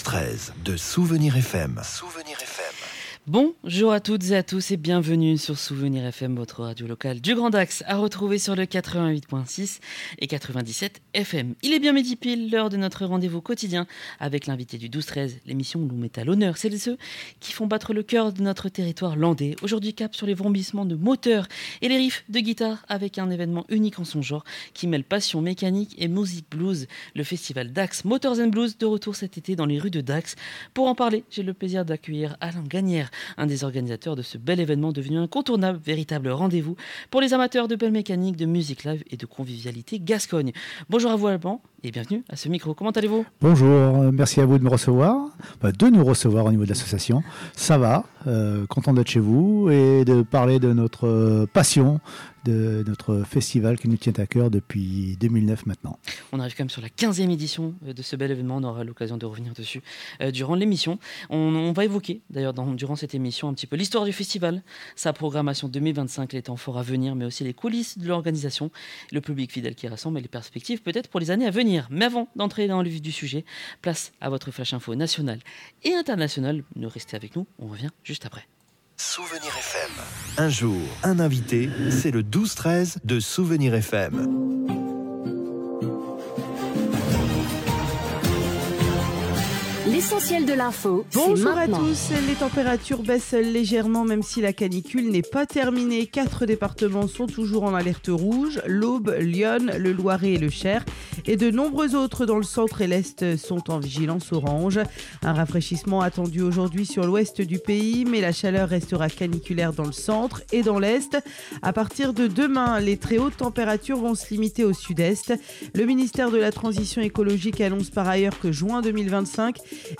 Un moment rythmé et enthousiasmant, à l’image de ce festival atypique, qui célèbre la culture motarde et musicale tout en renforçant les liens entre habitants, visiteurs, artistes et passionnés venus de toute la France voire de plus loin !